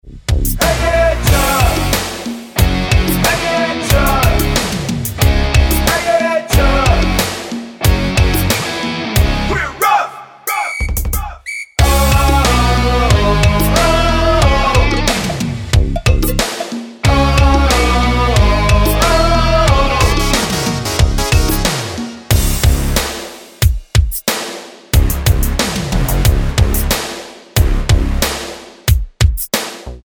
Tonart:C#m mit Chor